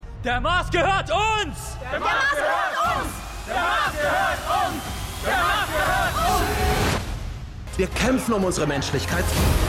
TRAILER:  HEIMAT: STAFFEL 5